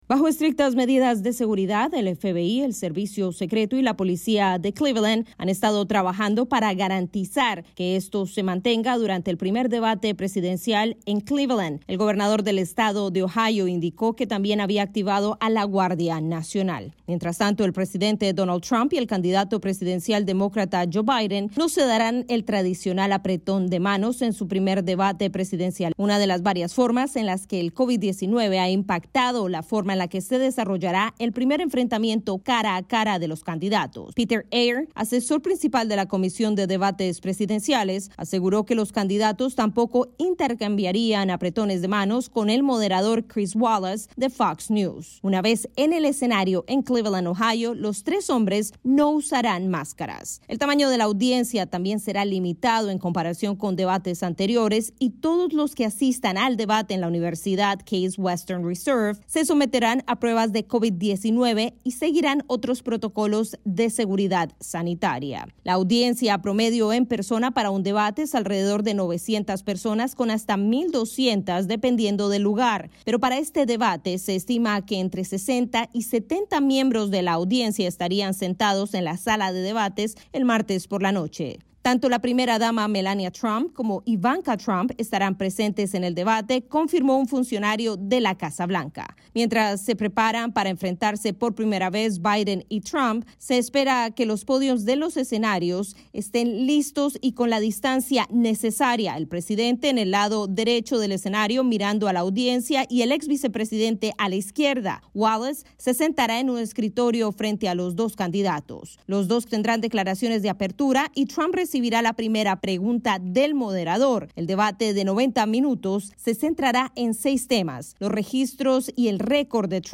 informe
desde Cleveland, Ohio.